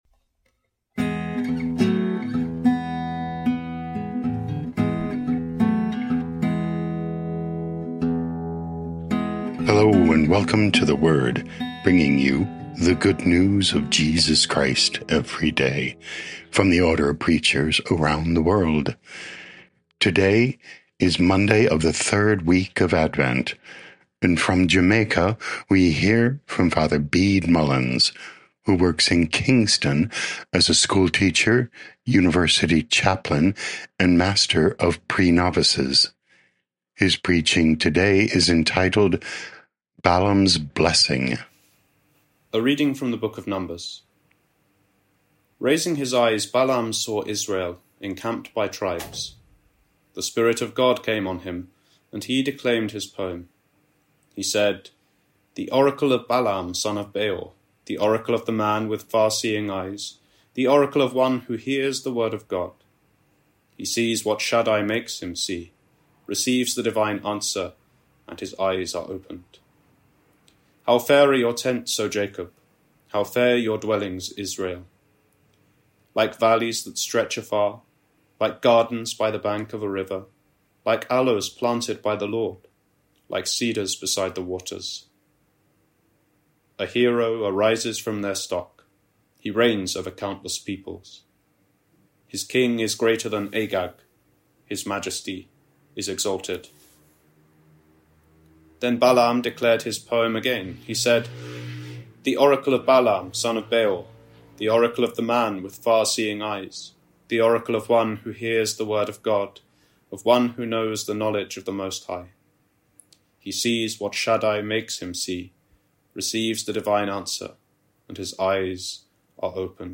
15 Dec 2025 Balaam’s Blessing Podcast: Play in new window | Download For 15 December 2025, Monday of the 3rd week of Advent, based on Numbers 24:2-7, 15-17a, sent in from Kingston, Jamaica.